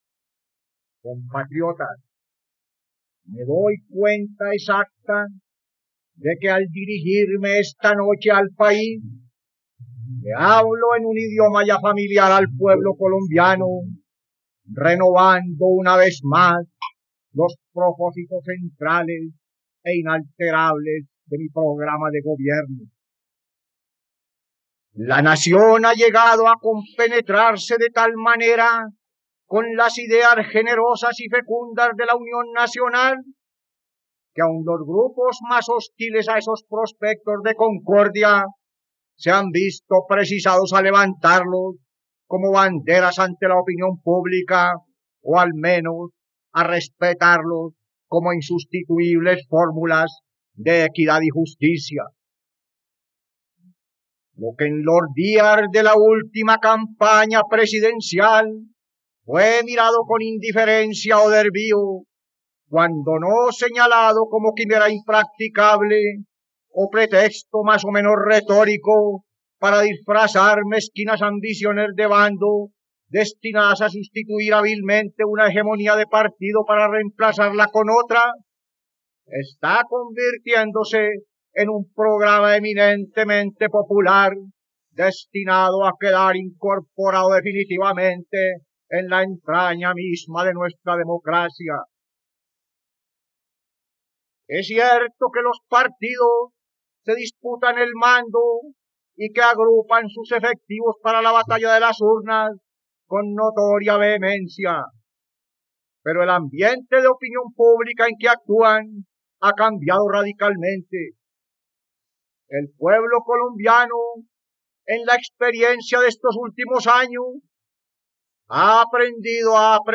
..Escucha ahora el discurso de Mariano Ospina Pérez sobre la unión nacional y la propuesta de una reforma electoral, el 3 de noviembre de 1948, en RTVCPlay.